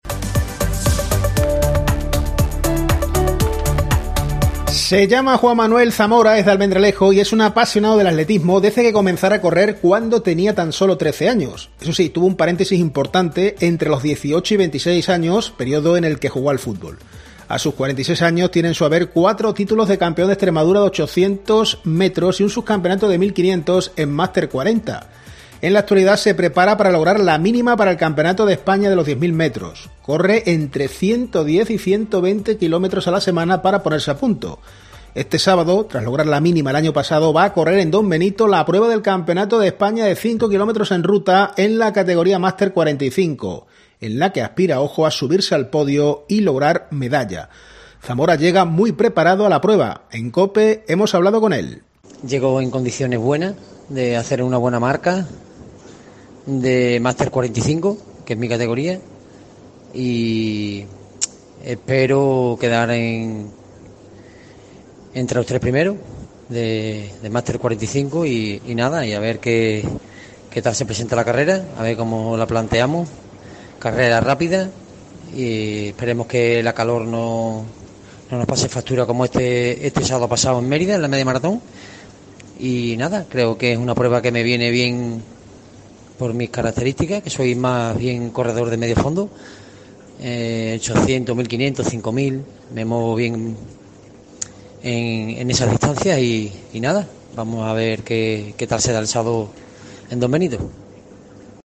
En COPE hemos hablado con él.